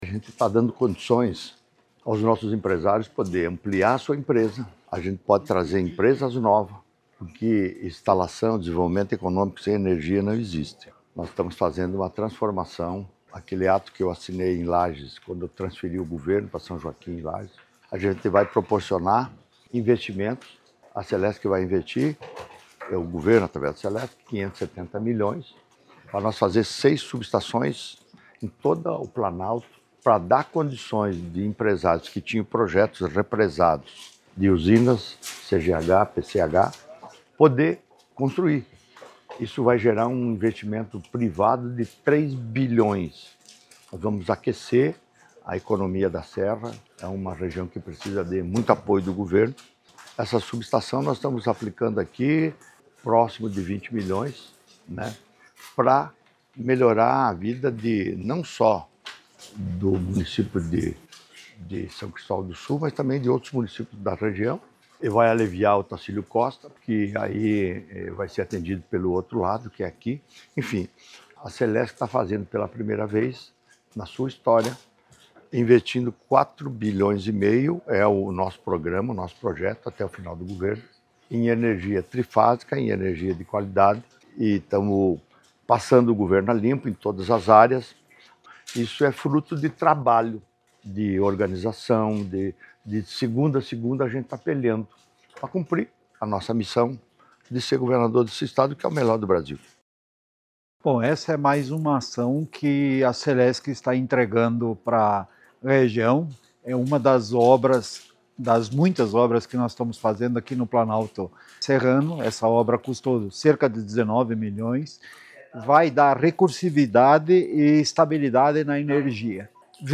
Durante a cerimônia, o governador Jorginho Mello destacou o impacto positivo do investimento: